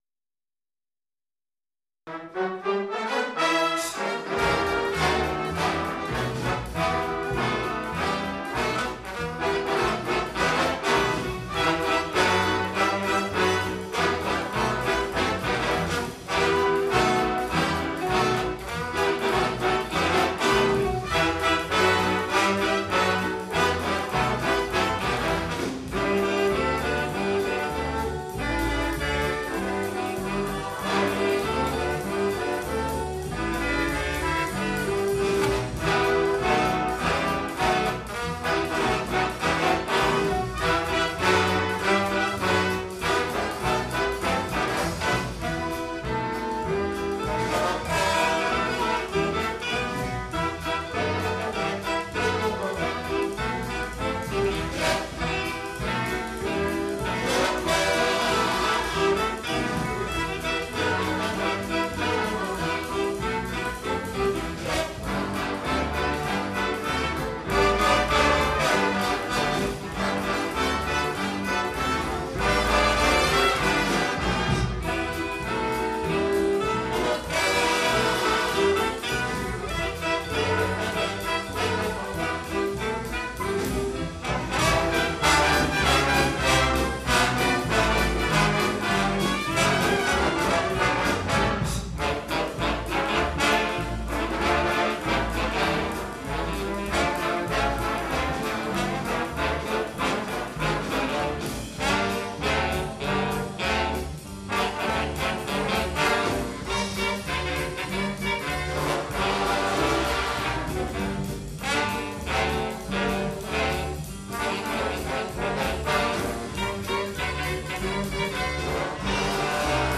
· Genre (Stil): Big Band